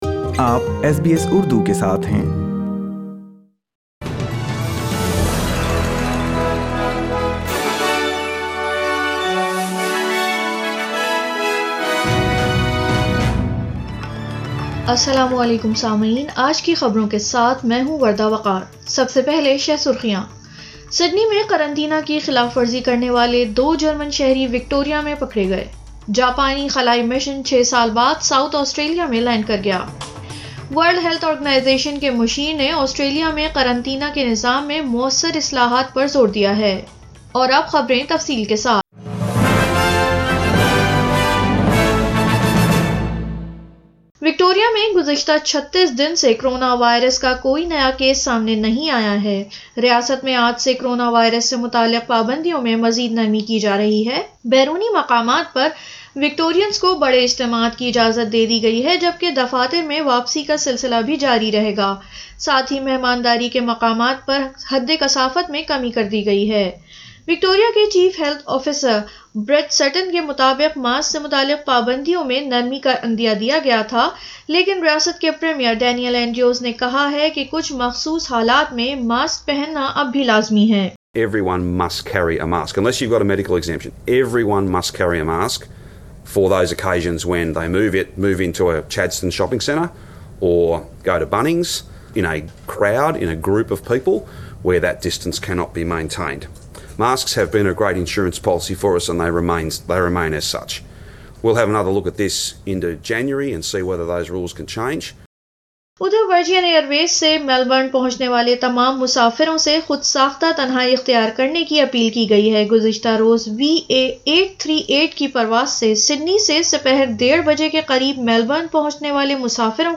اردو خبریں 6 دسمبر 2020